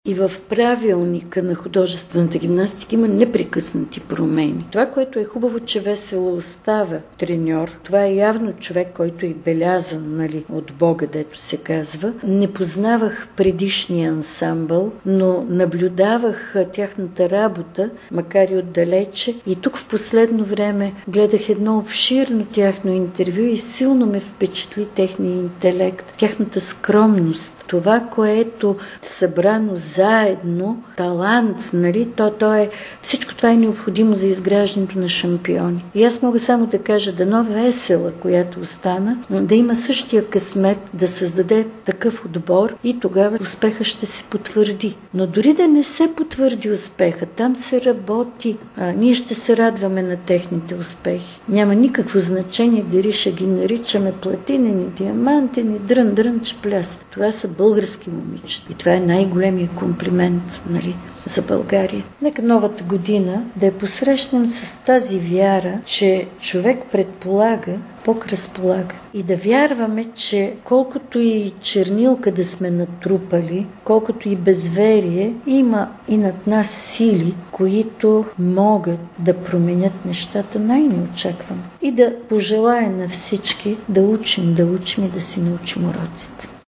Дори и да има грешки и несполуки, дори и да сме се излъгали с новите управляващи, промяната, която се случи в България е крачка напред в развитието на страната. Това коментира в интервю за Дарик Нешка Робева.